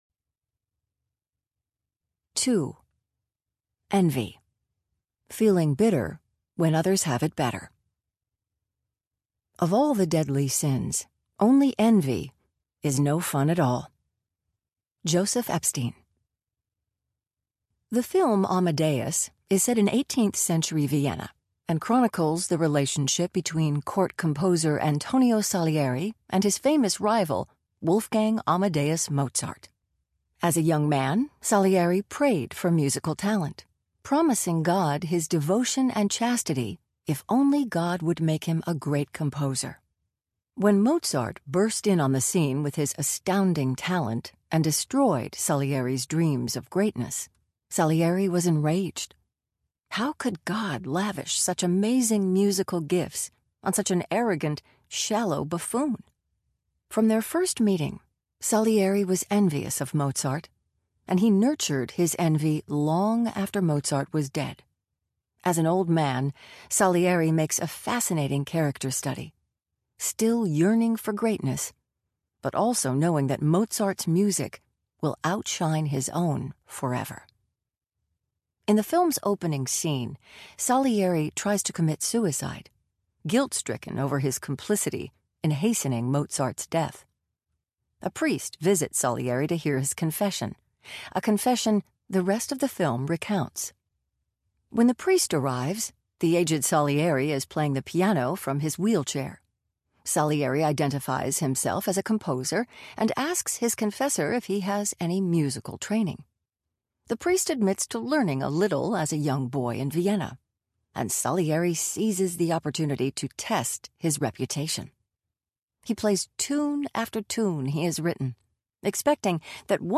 Glittering Vices Audiobook
8.6 Hrs. – Unabridged